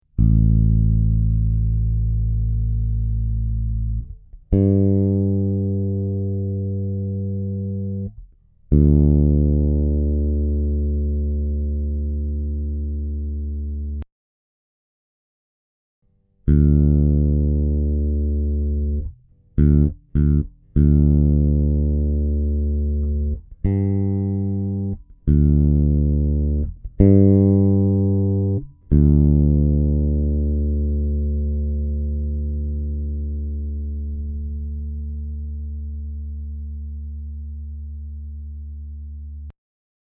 Hörbar ist das vor allem, wenn die Seite leer gespielt wird, im vergleich zu den anderen Seiten verändert sich der Ton rasch nach dem anspielen. Es wirkt so, als ob schnell nur noch die Höhen da wären (oder auf jeden fall schneller als bei anderen Saiten). Ich habe eine Aufnahme angehängt, allerdings hört man die Veränderung auf der Aufnahme nicht so gut...
Achja: mein Bass ist ein Ibanez SR 605 mit Optima Gold Strings bestückt.